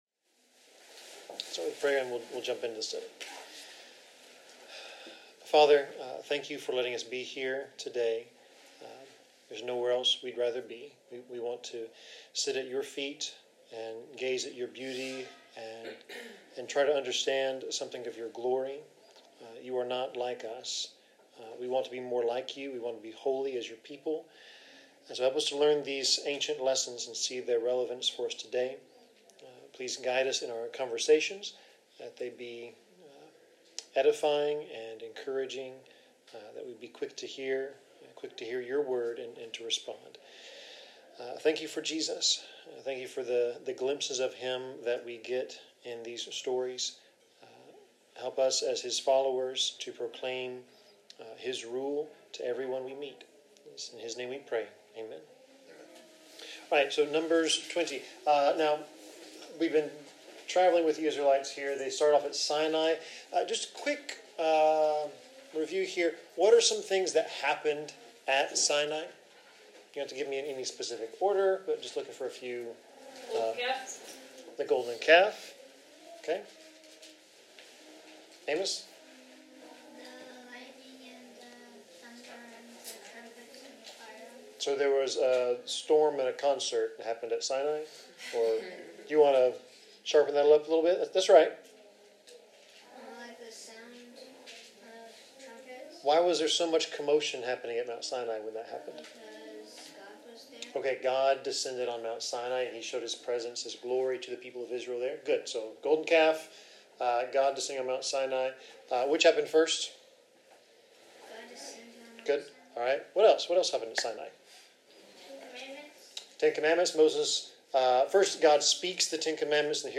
Bible class 11/20/2022